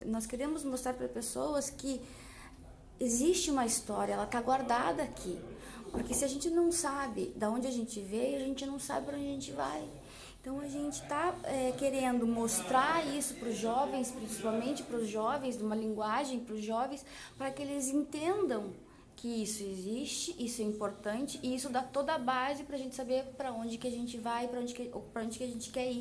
Elisandro Frigo, secretário de Administração e Tecnologia da Informação.